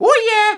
Super Mario Oh Yeah Sound Effect Free Download